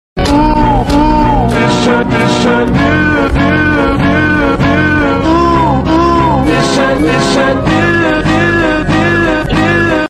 Jurassic World analog horror sound effects free download